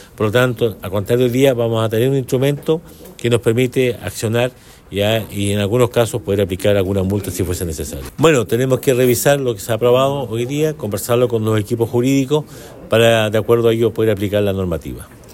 Del mismo modo el jefe comunal explicó que esta normativa debe pasar por la Unidad Jurídica del municipio, para establecer cuáles serían las sanciones que se pueden aplicar a la empresas que incumplan con esta.